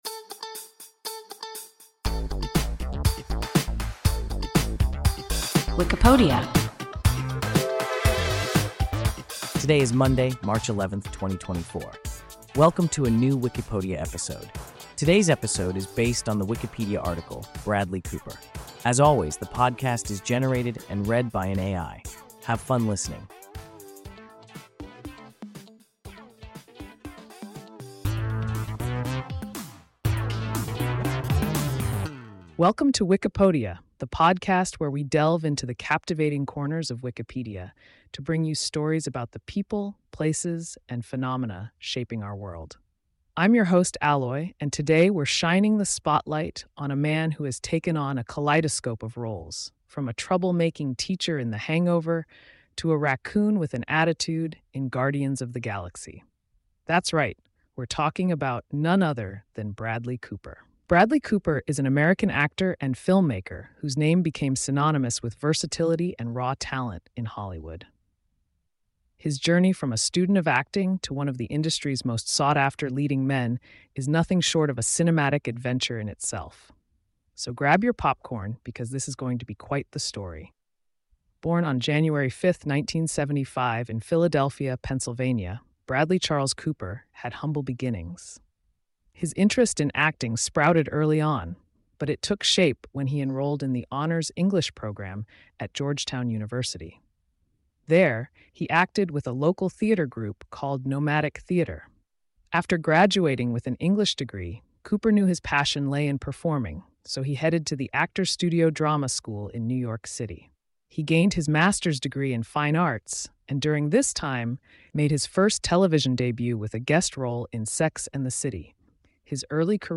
Bradley Cooper – WIKIPODIA – ein KI Podcast